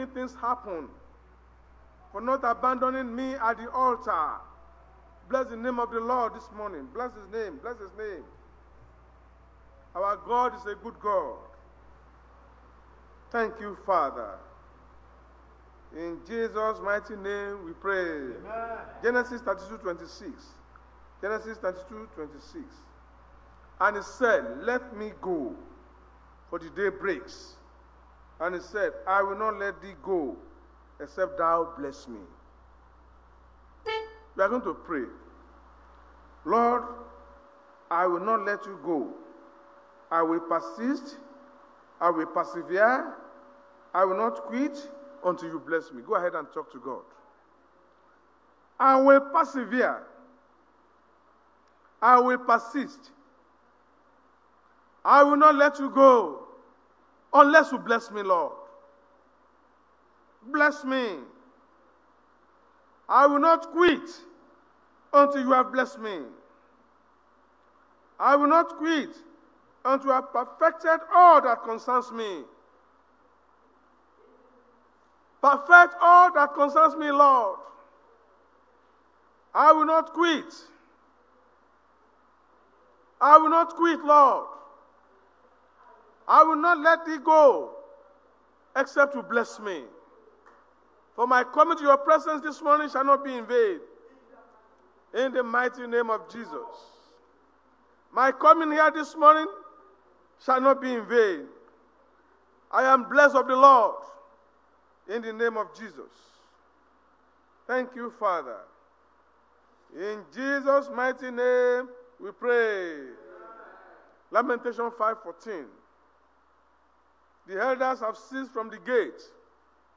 Sunday Message: The I Am That I Am
Service Type: Sunday Church Service